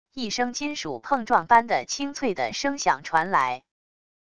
一声金属碰撞般的清脆的声响传来wav音频